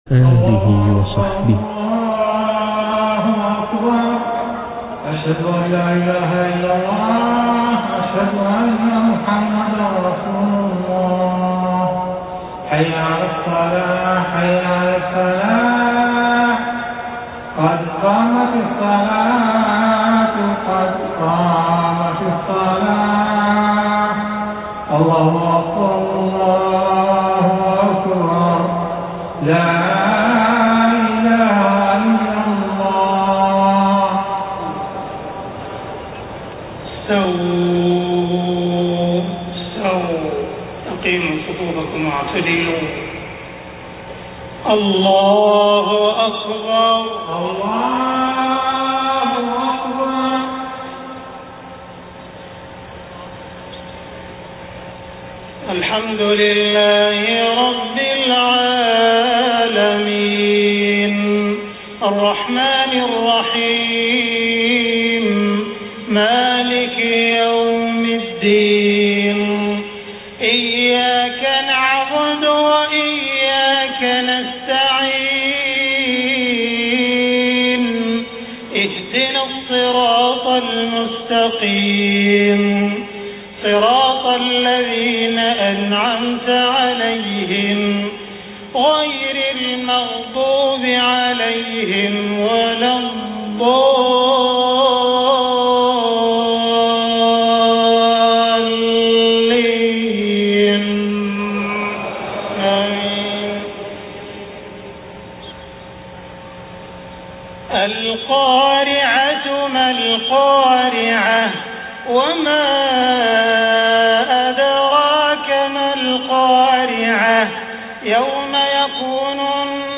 صلاة المغرب 1 صفر 1430هـ سورتي القارعة والهمزة > 1430 🕋 > الفروض - تلاوات الحرمين